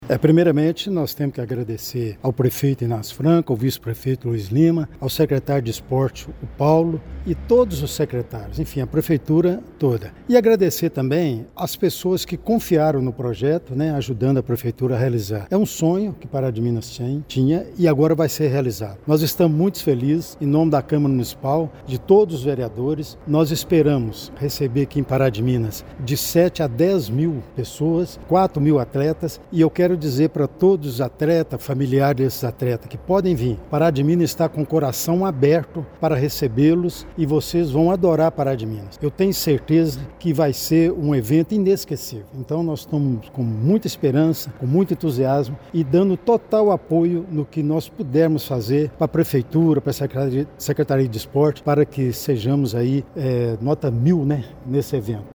O presidente da Câmara Municipal, Délio Alves Ferreira (PL), destacou o engajamento coletivo para receber os jogos, uma vez que Pará de Minas abraçou esse projeto para receber atletas e familiares de braços abertos: